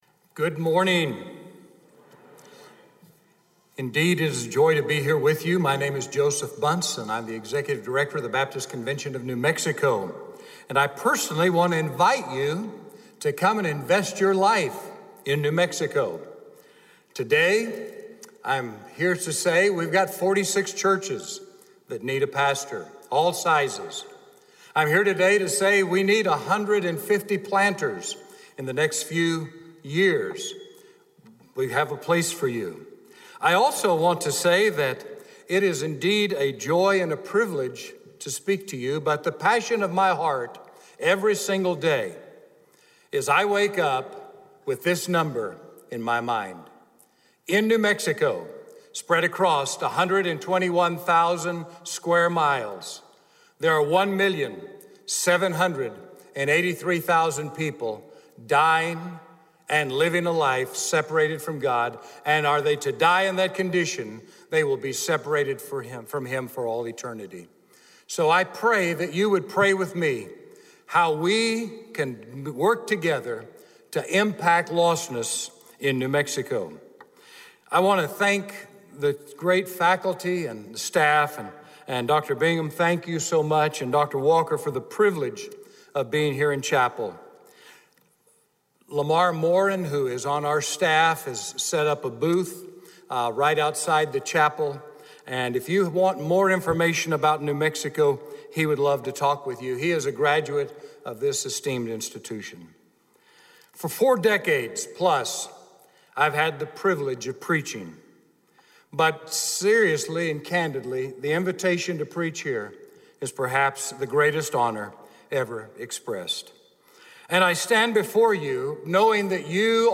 in SWBTS Chapel on Tuesday February 5, 2019
SWBTS Chapel Sermons